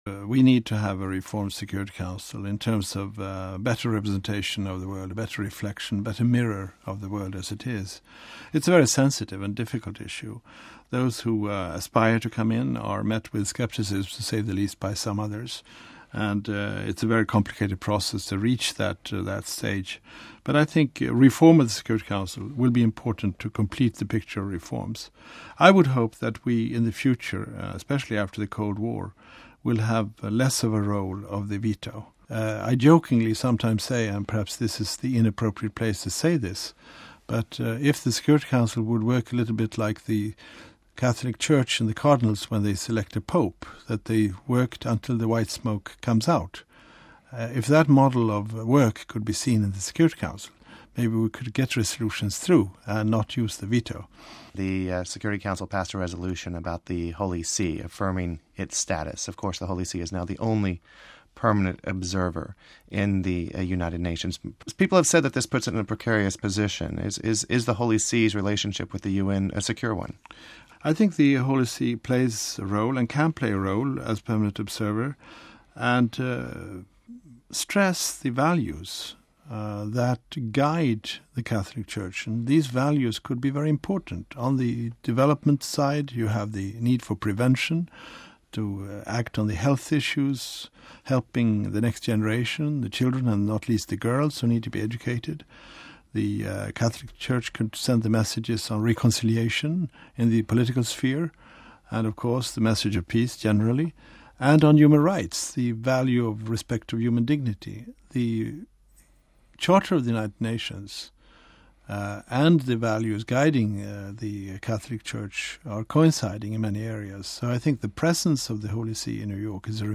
Interview With General Assembly President